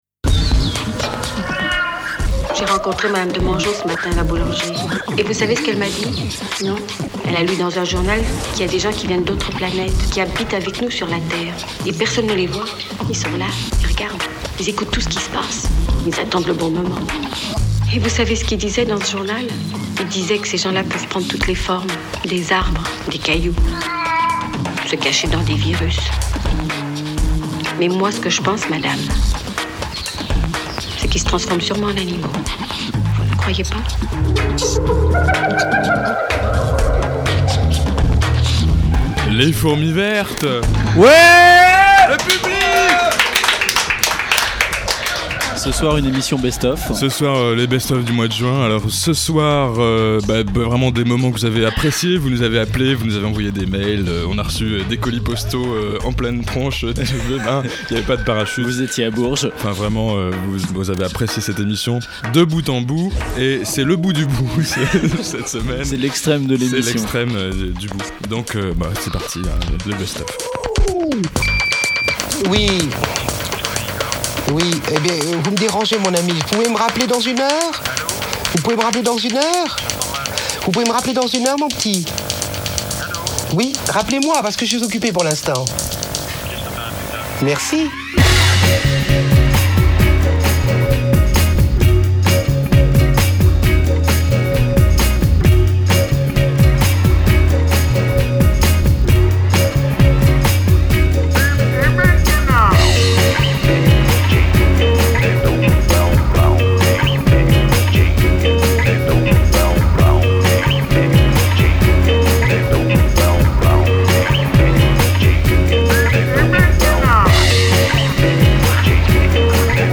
diffusion originale : radio Pulsar
spécificités : direct qui part en live
un groupe d'amis au téléphone